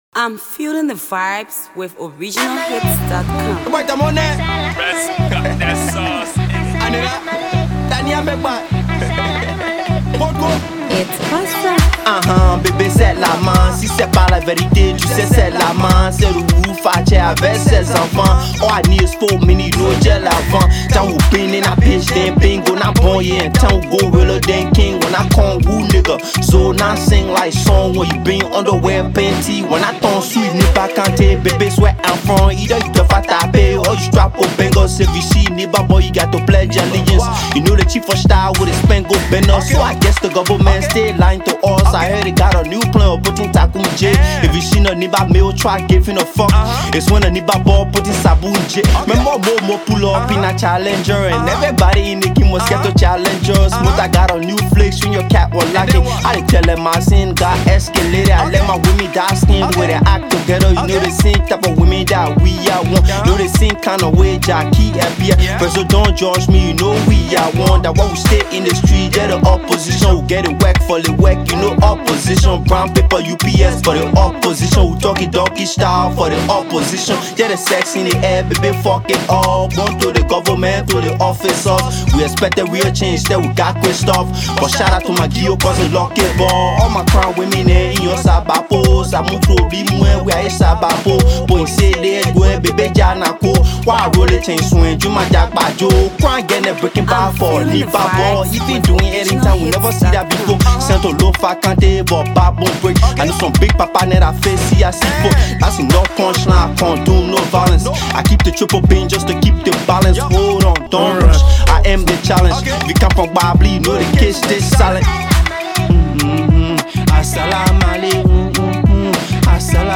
AfroAfro PopMusic